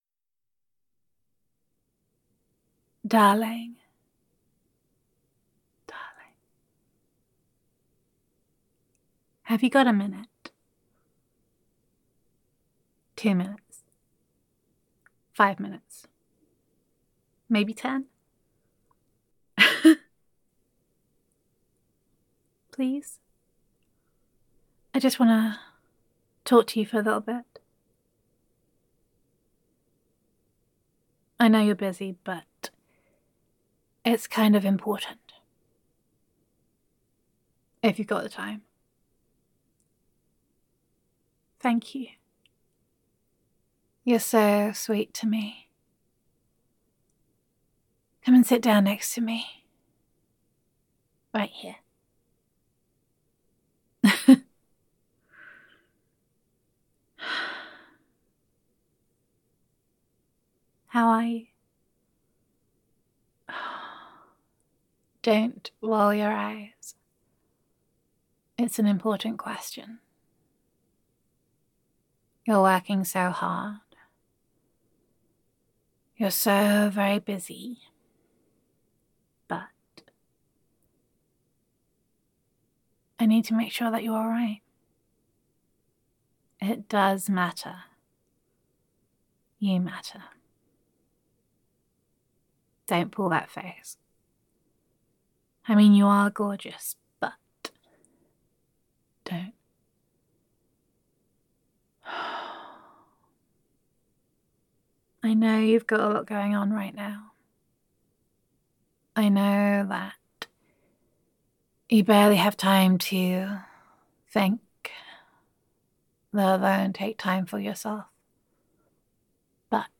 [F4A] The Way of the Cuddle Ninja [Girlfriend Roleplay][There Is a New Master Snuggler][Comfort][You’re Important][I’m in It for the Cuddles][Whispers][Snuggles][Cuddles][You Are the Sensei of Snuggling][Giggling][Kisses][Gender Neutral][The Reign of the Cuddle Ninja Is Over, Long Live the Cuddle Ninja]